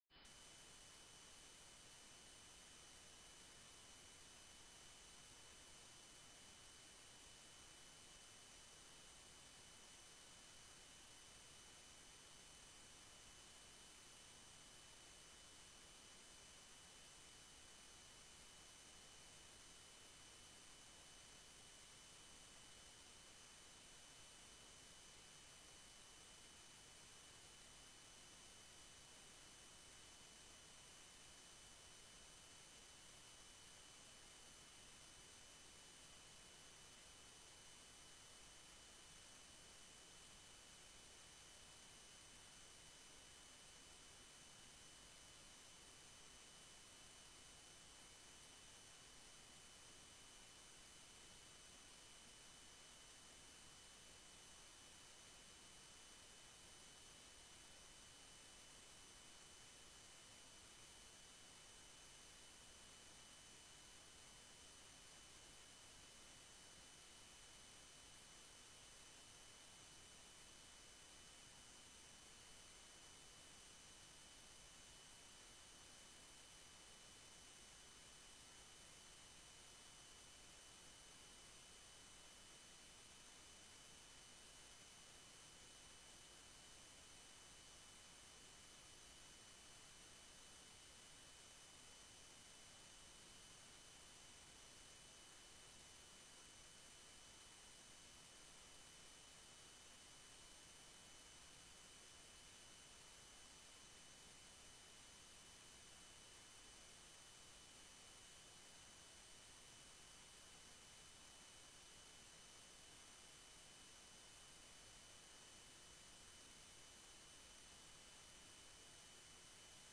gemeenteraad 23 februari 2012 19:30:00, Gemeente Doetinchem
Locatie: Raadzaal